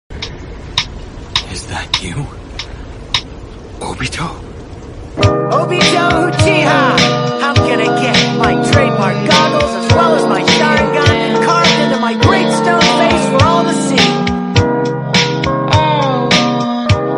is that you obito Meme Sound Effect